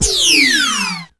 Index of /90_sSampleCDs/E-MU Producer Series Vol. 3 – Hollywood Sound Effects/Science Fiction/Columns
DECENDING 02.wav